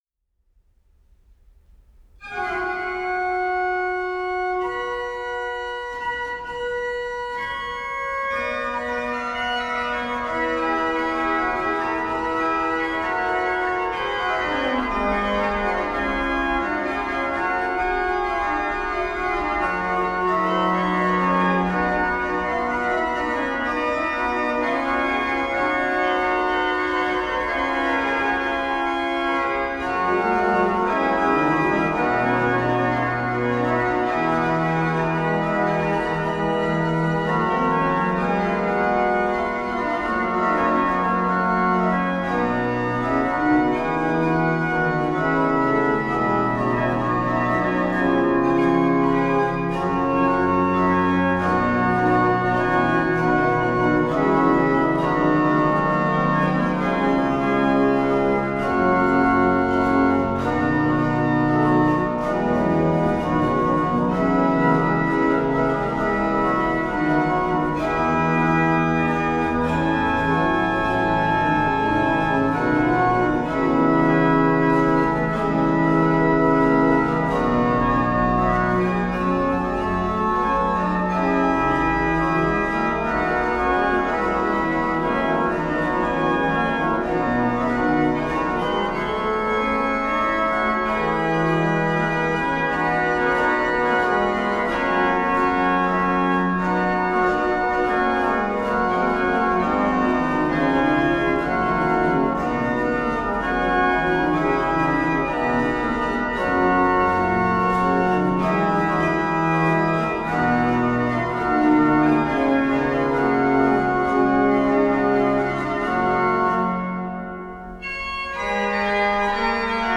Volume 1 is organ music and vocal music